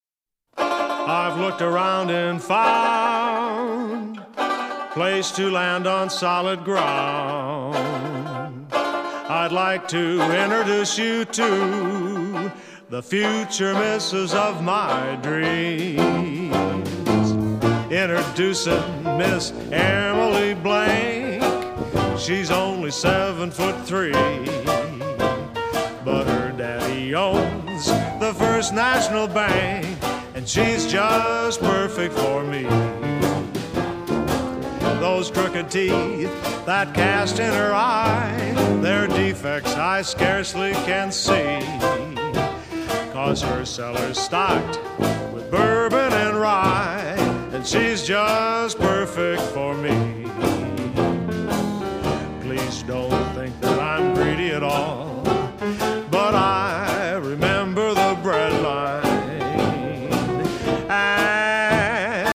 cornet
plectrum/banjo